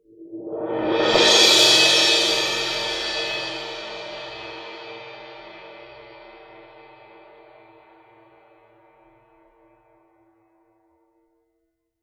Percussion
susCymb1-cresc-Short_v1.wav